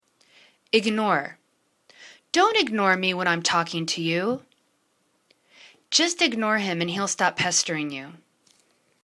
ig.nore     /ignaw:r/    v